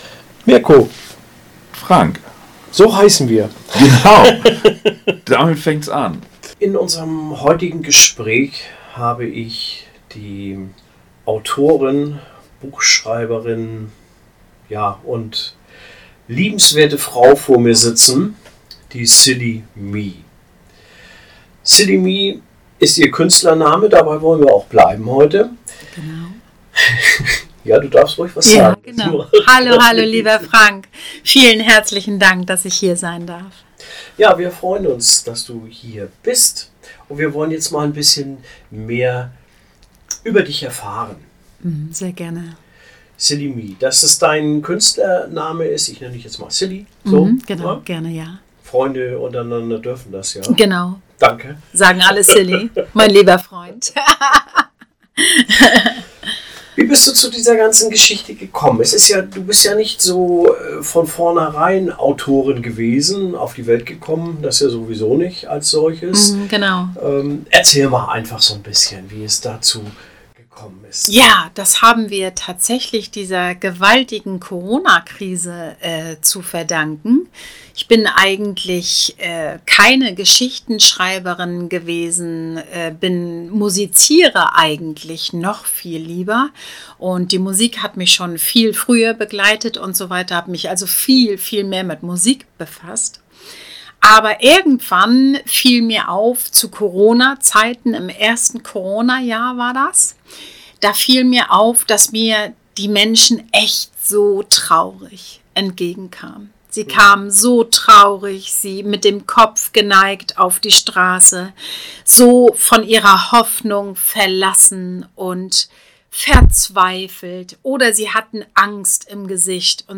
Hier erzählt sie ein wenig aus ihrem Leben!